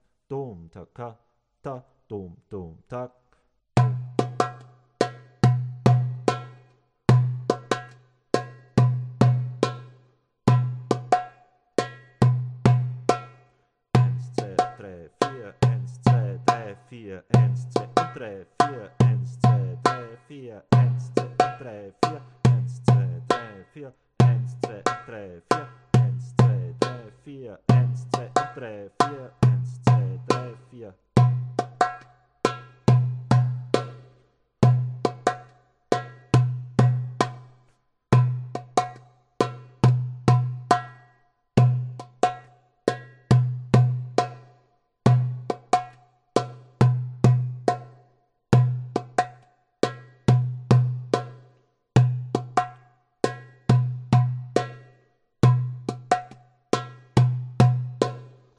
Darbouka workshop
Die Darbouka (oder Doumbec, Tabla) ist eine vasenförmige Trommel die im gesamten arabischen Raum
verbreitet ist und bereits mit einfacher Schlagtechnik sehr schöne Klänge und Rhythmen produziert.
da es sich um Übungen für Anfänger handelt, sind alle Rhythmen Hand to Hand zu spielen;
26. Übung zum Chifteteli: Dum - TaKa / - - Tak - / Dum - Dum -/ Tak - - - //
27.     schneller